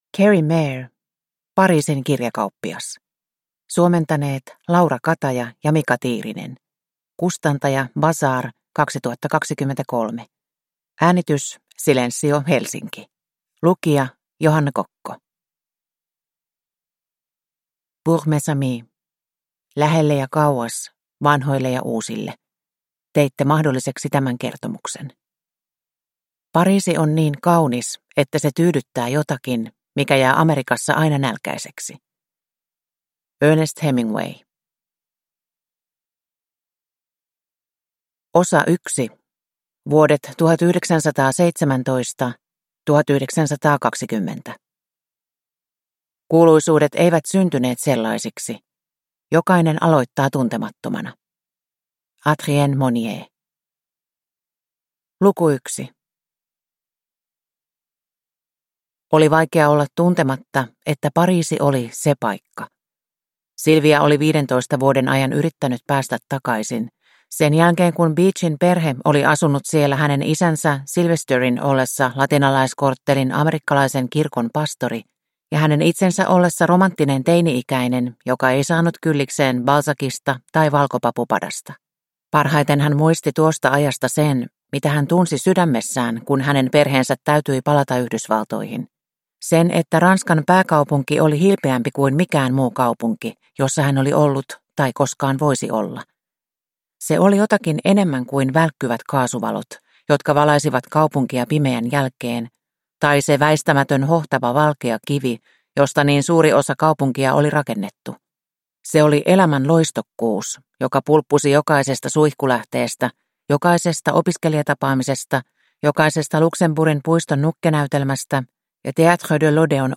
Pariisin kirjakauppias – Ljudbok – Laddas ner